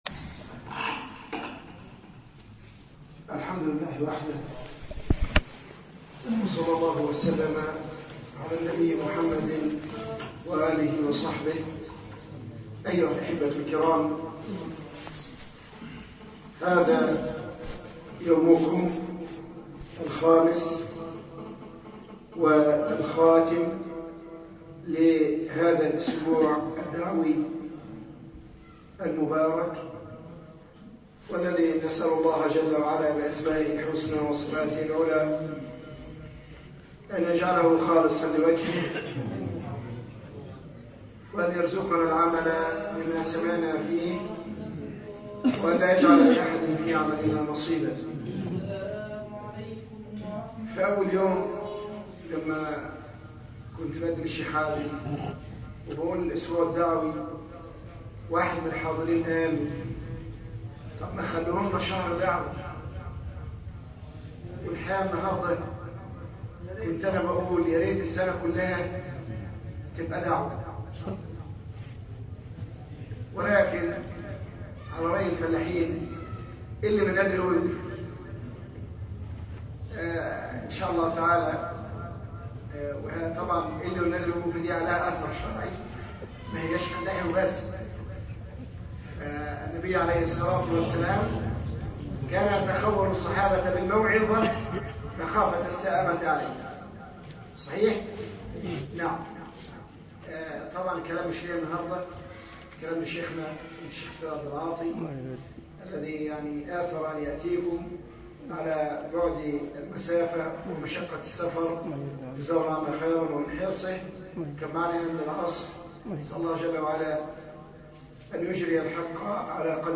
مسجد بمحافظة المنوفية محاضرة